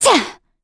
Valance-Vox_Attack6_kr.wav